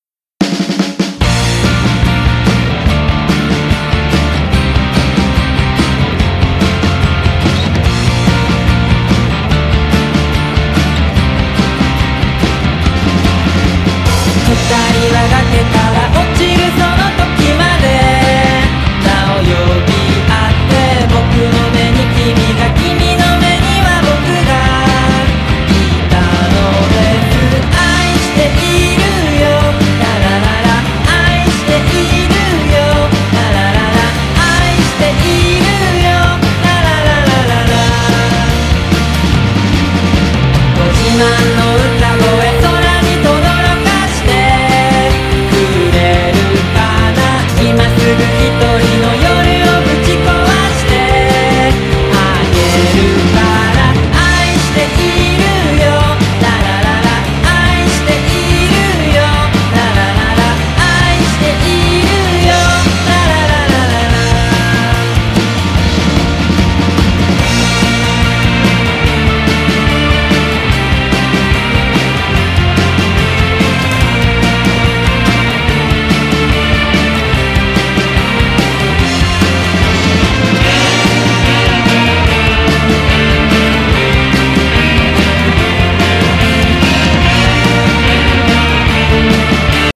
SSW / FOLK# 90-20’S ROCK
Vocal,Chorus,Bass
Vocal,Chorus,A.Guitar,E.Guitar,Keyboard
Chorus,Drums,Percussion